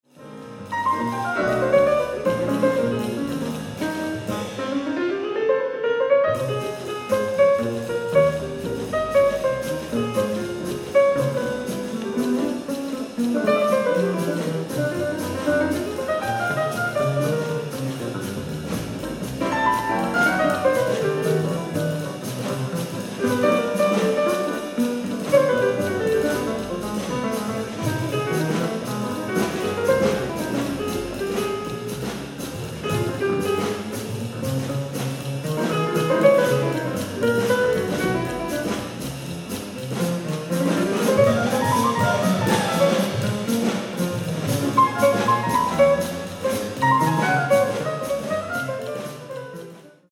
ライブ・アット・オペラハウス、コペンハーゲン 07/09/2011